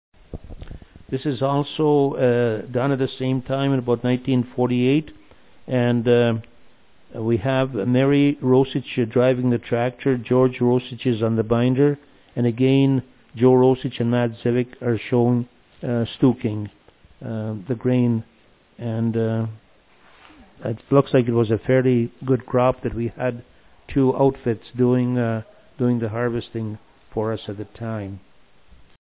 Bindering Grain - Sound Clip